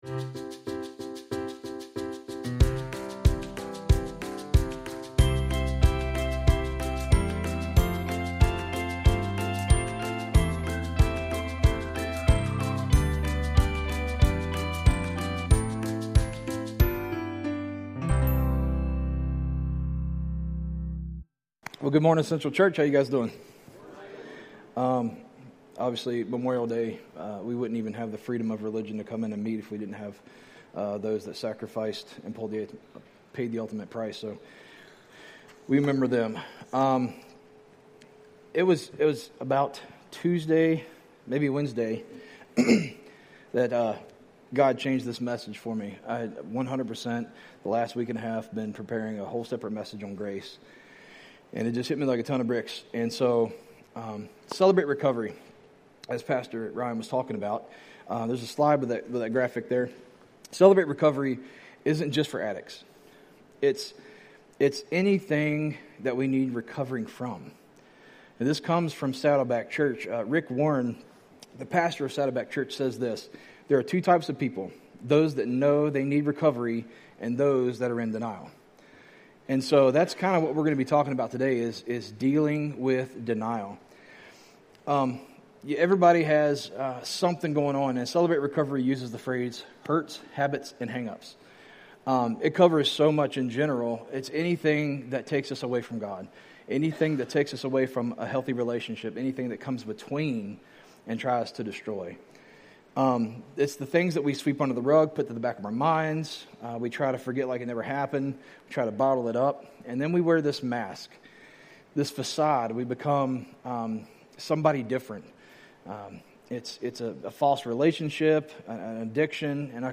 This week's sermon was "Dealing With Denial" is based on 1 John 1:8